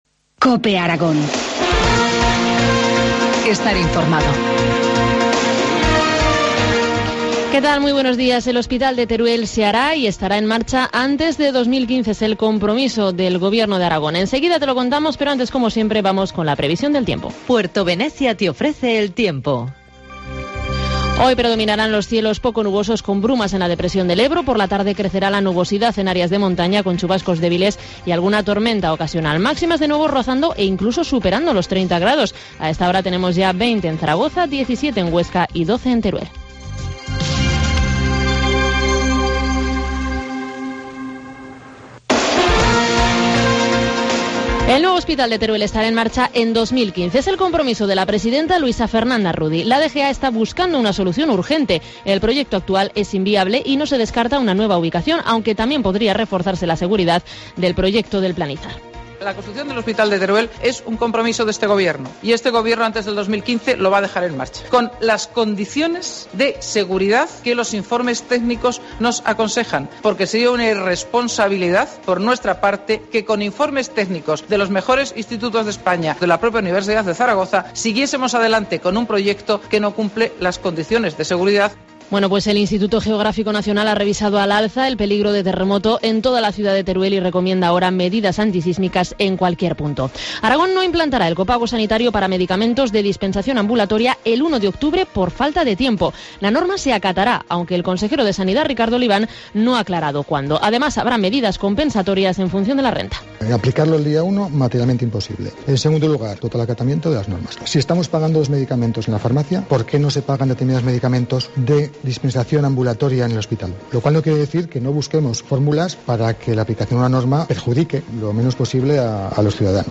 Informativo matinal, miércoles 25 de septiembre, 8.25 horas